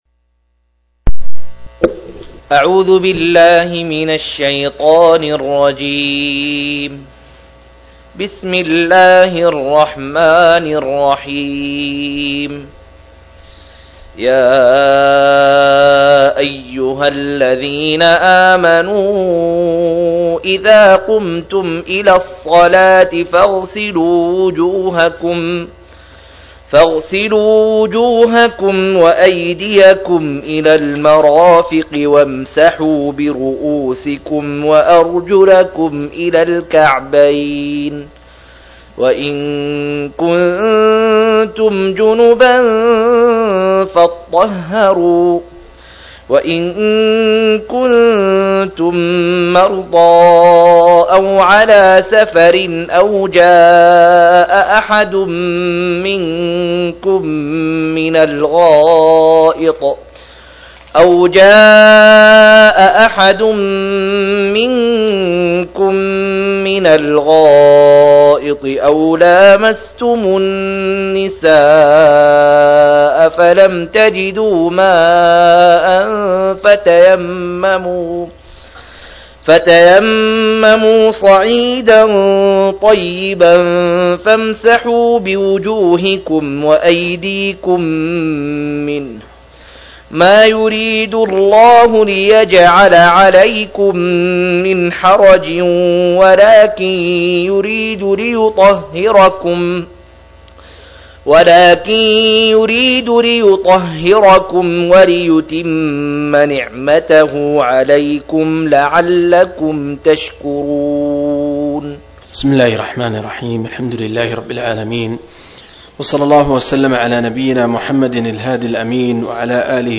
110- عمدة التفسير عن الحافظ ابن كثير رحمه الله للعلامة أحمد شاكر رحمه الله – قراءة وتعليق –